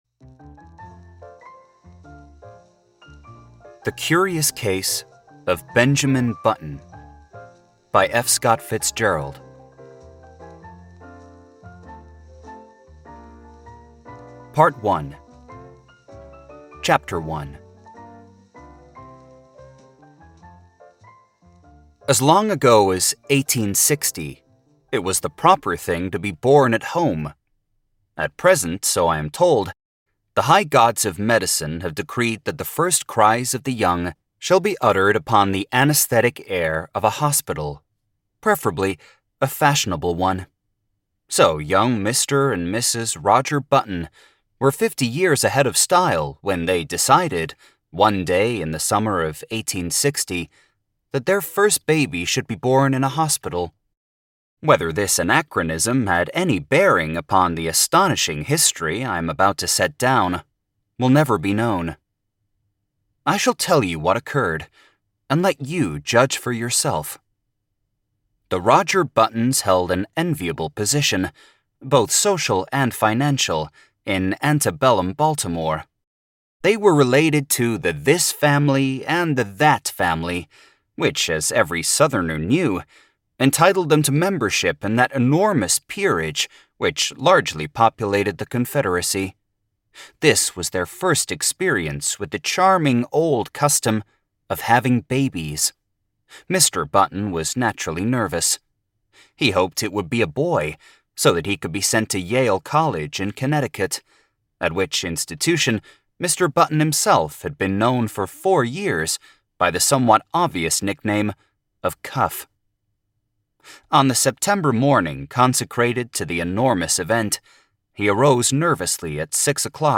Robin Hood by Andrew Lang - Relaxing Bedtime Adventure Audiobook